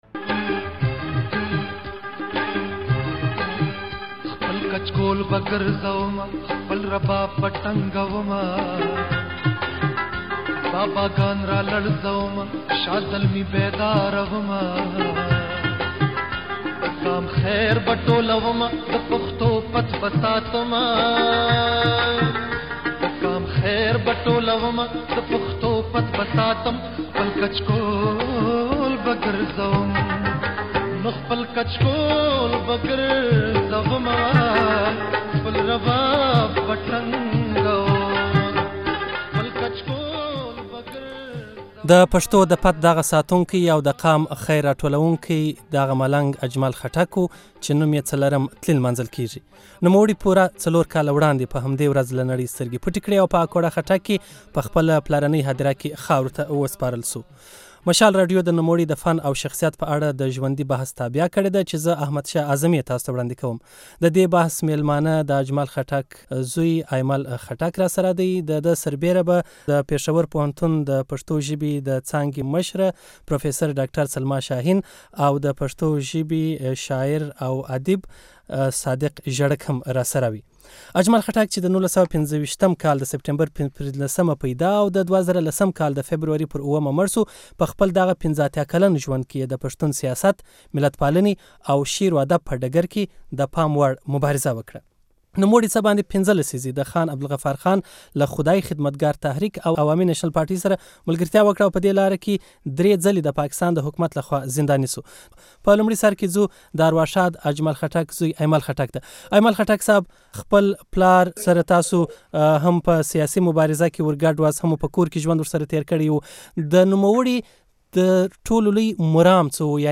د ځانګړي بحث غږ